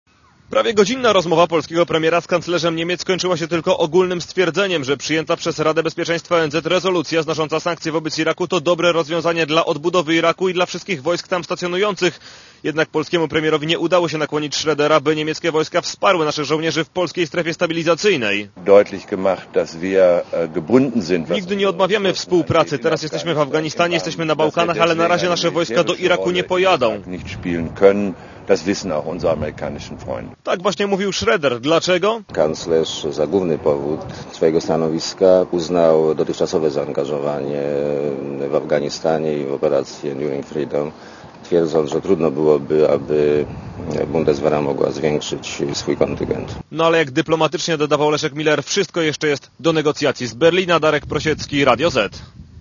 Z Berlina specjalny wysłannik Radia Zet